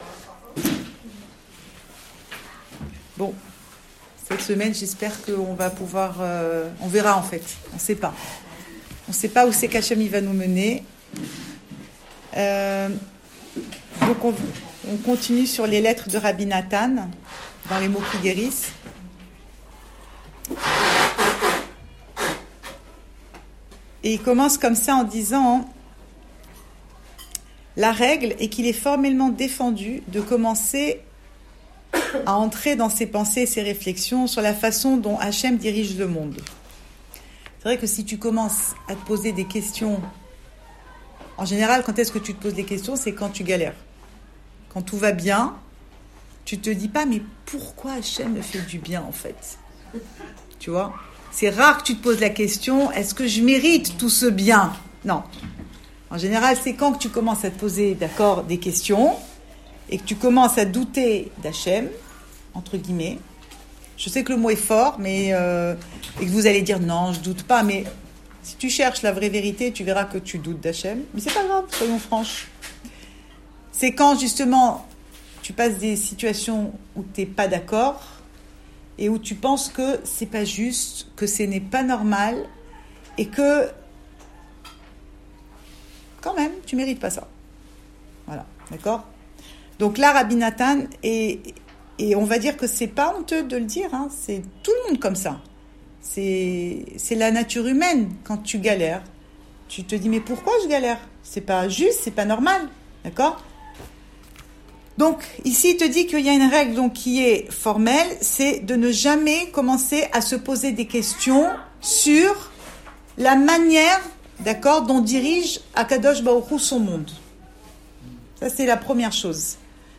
Cours audio Le coin des femmes Pensée Breslev Vie de couple - 4 février 2020 9 février 2020 Chaud ou froid ?
Enregistré à Raanana